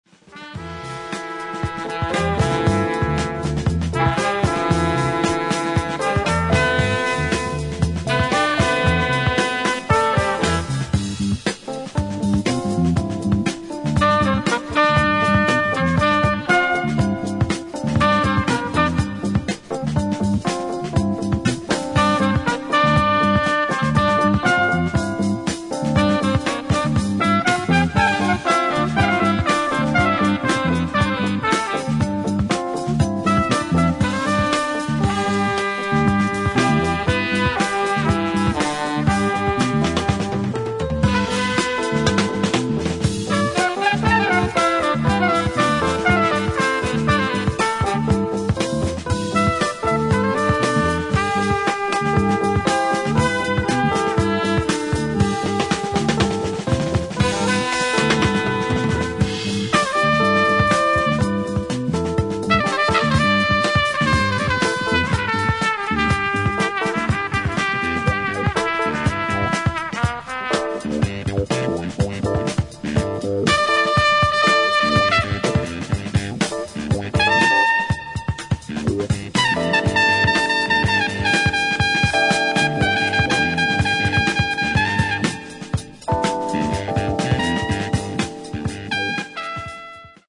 レアグルーブ〜フリージャズ・ファンまでおすすめの名作アルバムです。